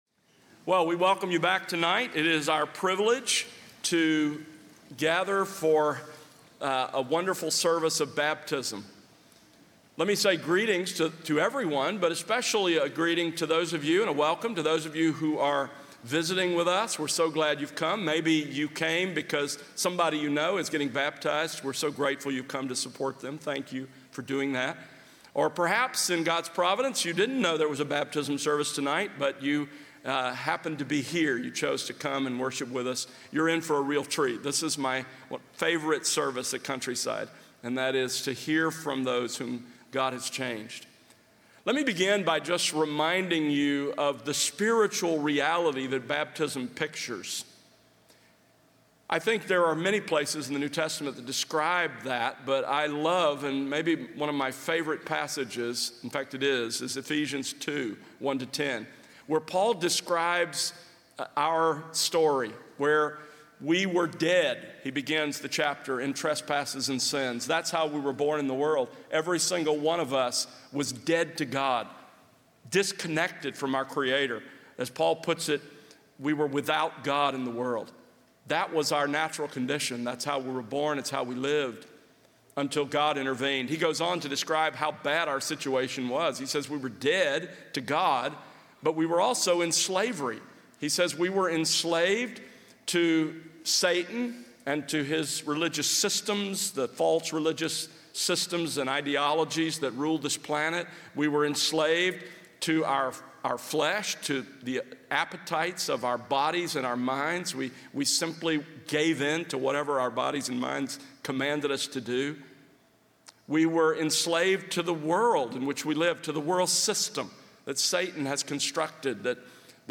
Baptisms
Audio Baptisms 2025-07-20 pm Worship Services Baptism Services Audio Video ◀ Prev Series List Next ▶ Previous 82.